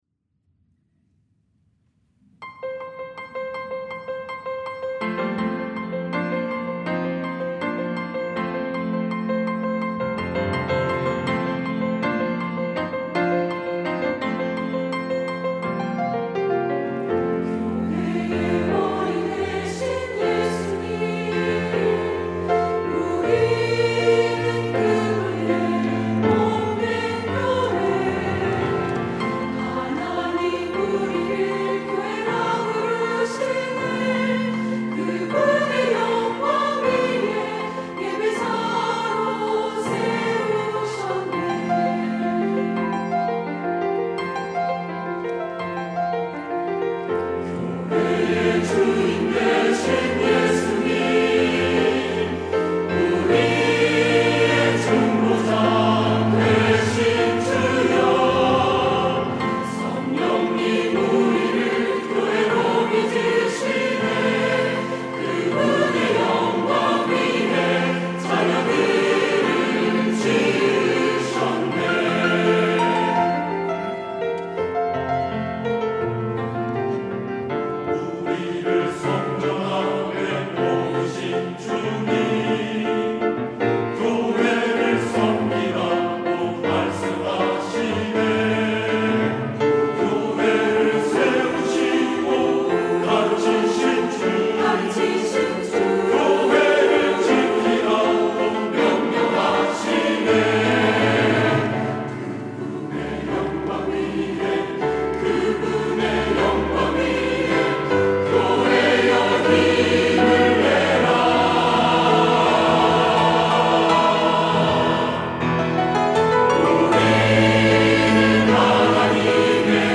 할렐루야(주일2부) - 우리는 하나님의 교회
찬양대